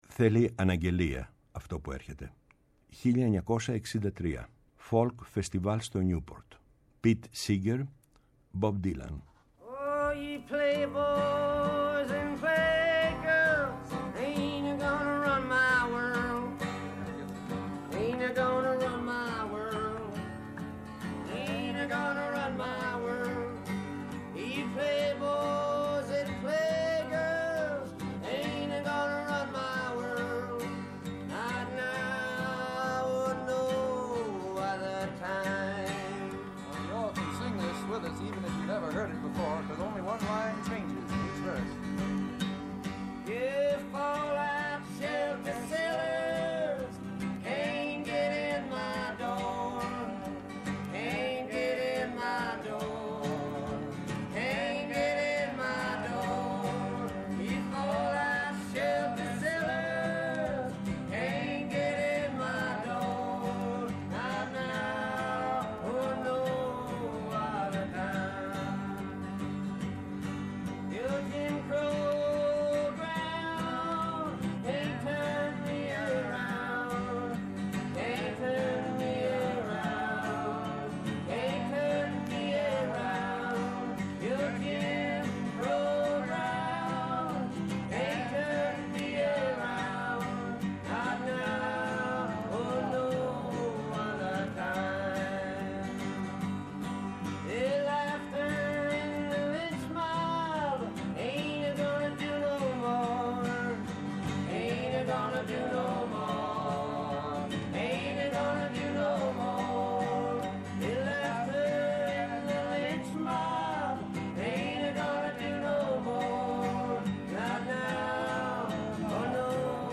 Η μακροβιότερη εκπομπή στο Ελληνικό Ραδιόφωνο!
Από το 1975 ως τον Ιούνιο 2013 και από το 2017 ως σήμερα, ο Γιάννης Πετρίδης βρίσκεται στις συχνότητες της Ελληνικής Ραδιοφωνίας, καθημερινά “Από τις 4 στις 5” το απόγευμα στο Πρώτο Πρόγραμμα